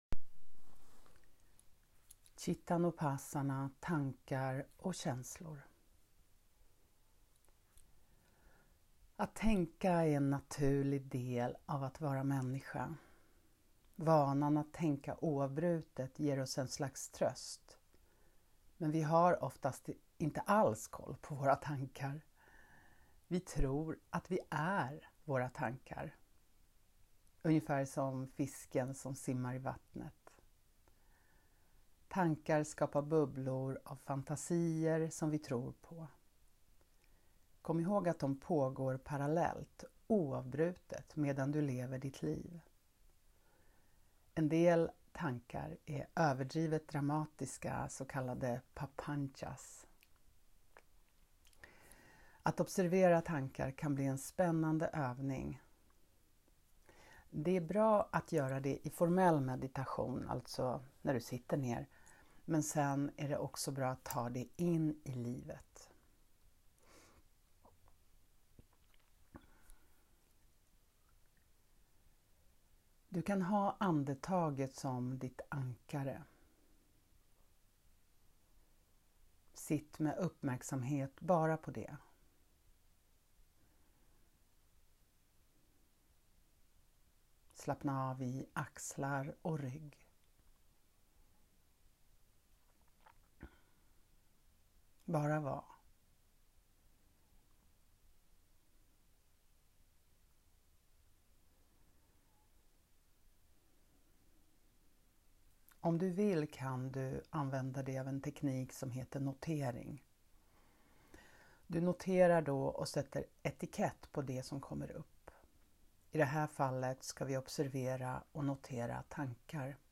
Cittanupassana – tankar & känslor, meditation